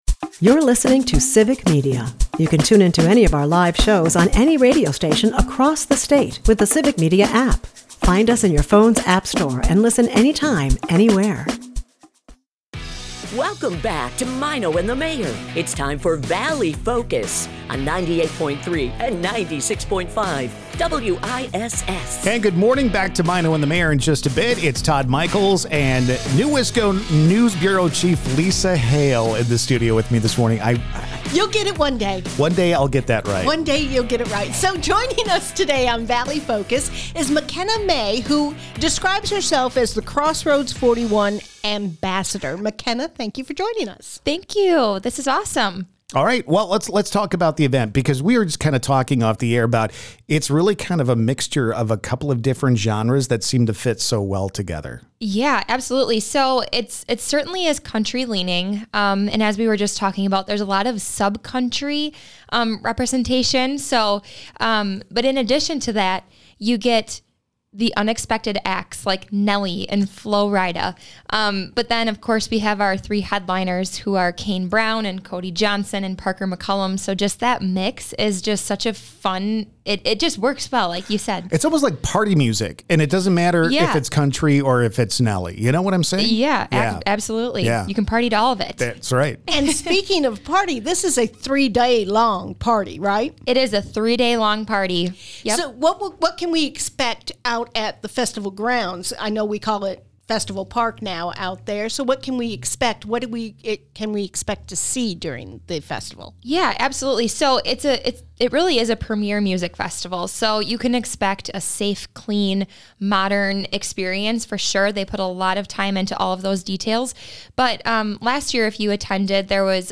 Valley Focus is a part of the Civic Media radio network and airs weekday mornings at 6:50 a.m. as part of the Maino and the Mayor Show on 96.5 and 98.3 WISS in Appleton and Oshkosh.